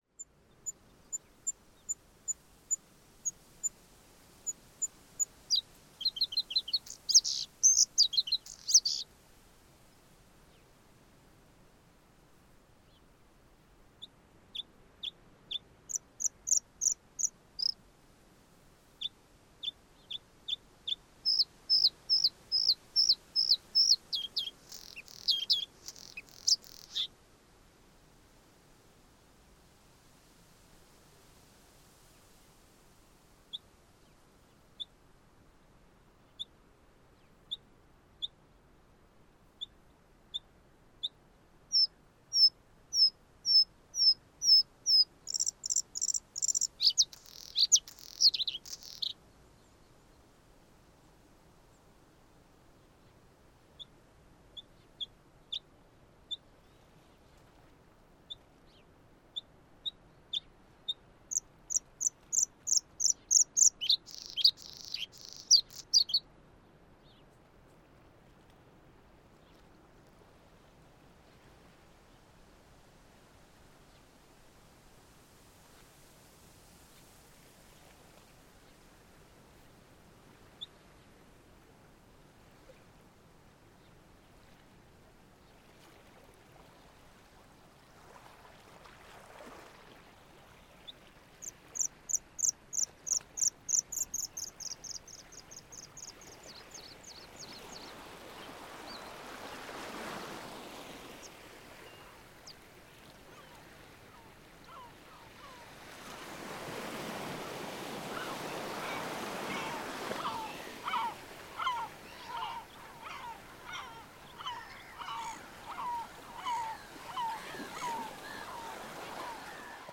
ユニーク且つ小気味良いサウンドが満載。
Ce quatrième opus de la collection « Galets sonores » regroupe une série d’enre-gistrements sur le thème des oiseaux, collectés dans différentes régions de France métropolitaine entre 2011 et 2024.